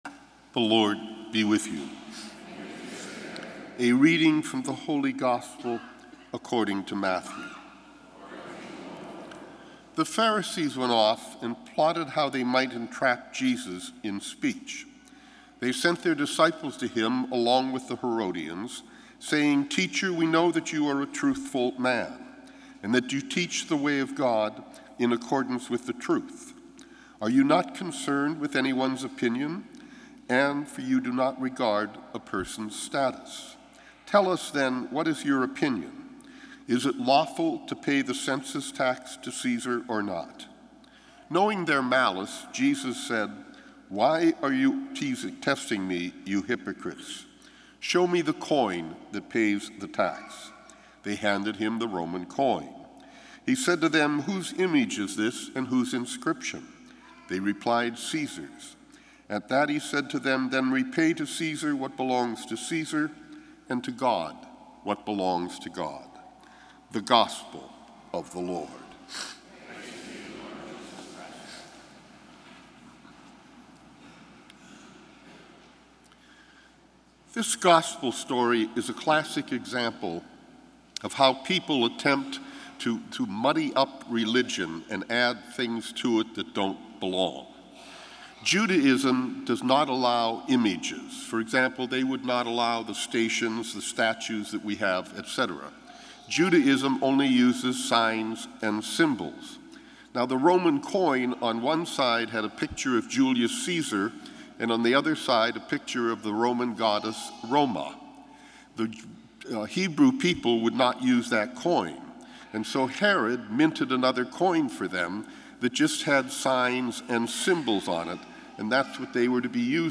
Gospel & Homily October 22, 2017